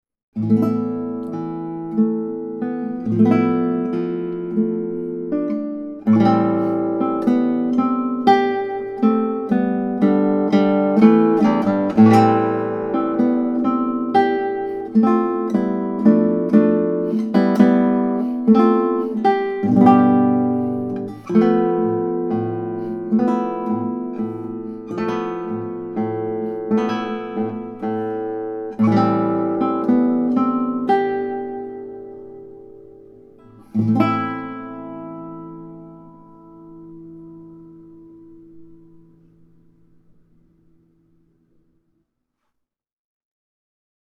Prelude 2 commences with a gentle two-measure vamp. The main motive enters at measure 3 and should be executed with vigor. The emotional energy continues building to measure 9, climaxing with a big, fat G chord. Thereafter, the mood subsides to thoughtful reflection and a softer, gentler final statement of the opening motive.
guitarist-composer